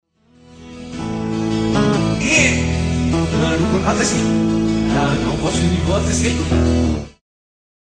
Genere: brazilian rock